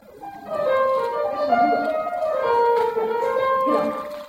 [1-4]如果四次都一样：